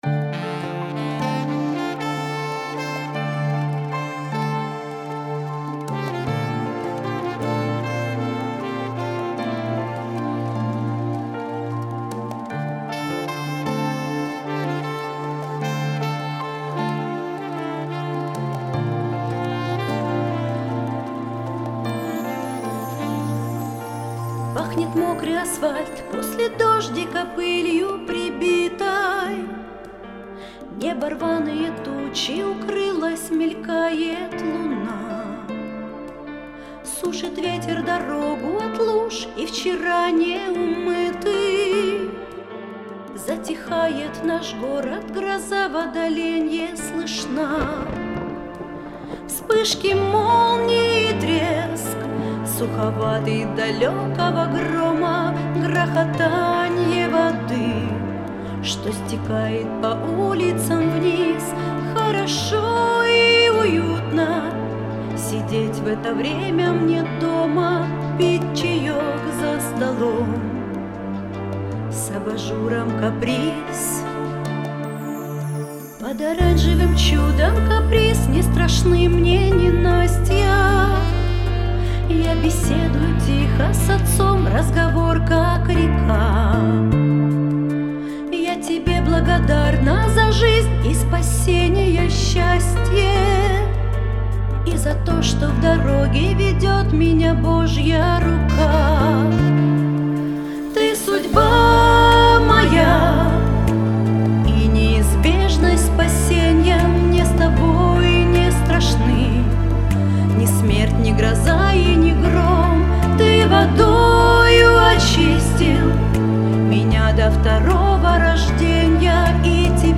200 просмотров 299 прослушиваний 21 скачиваний BPM: 81